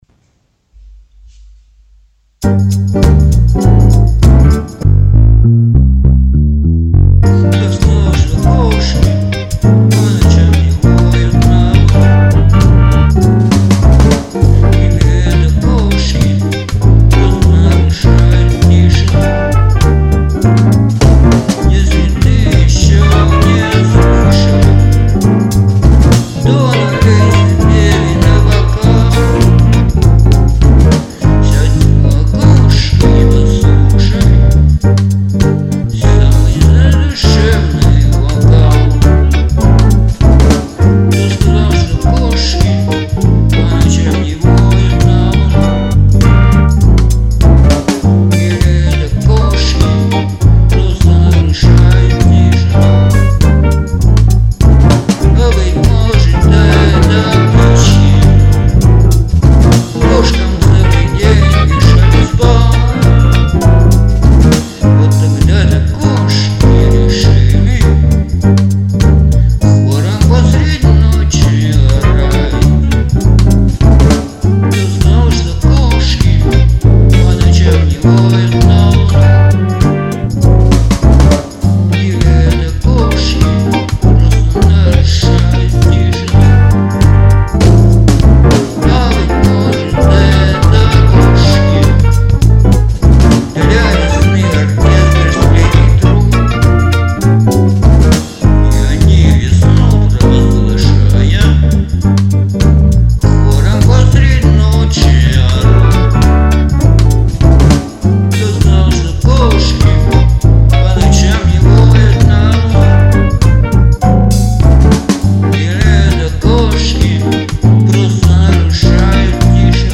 • Жанр: Регги